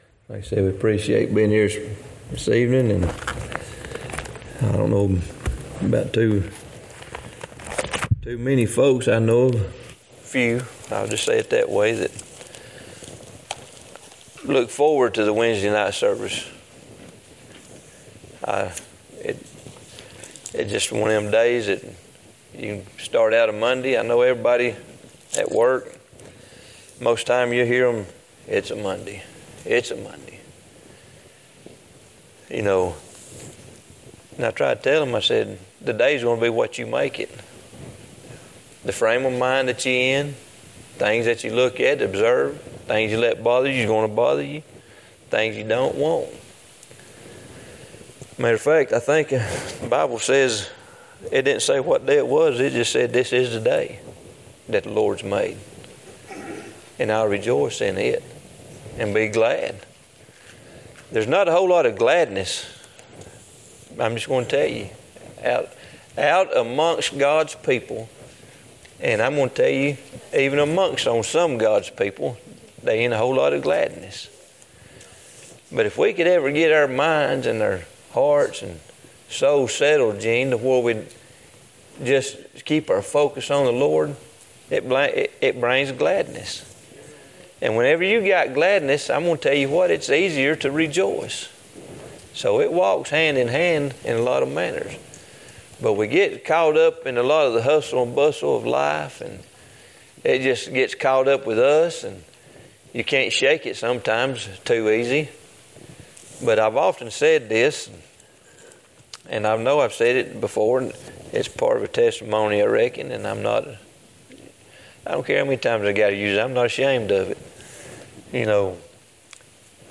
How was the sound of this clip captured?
Luke 14:12-14 Service Type: Wednesday night Topics